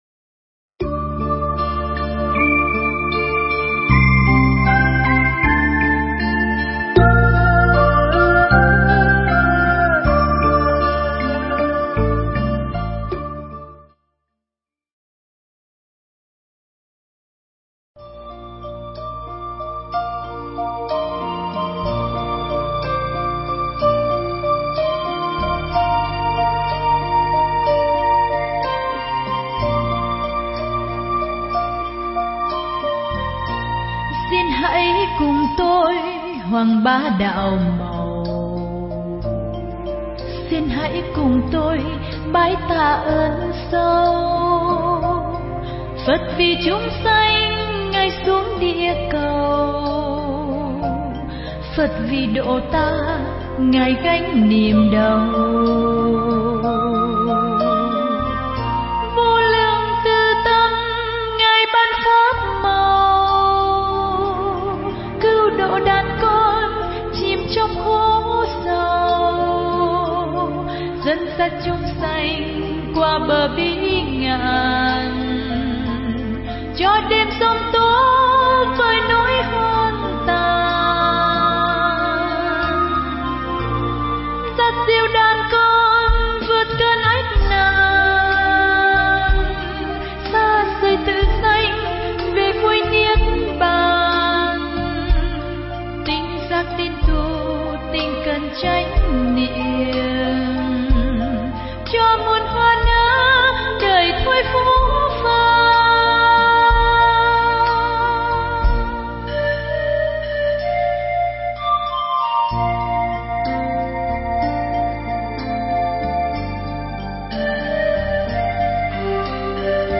Nghe Mp3 thuyết pháp Hoằng Pháp Tại New Zealand Và Australia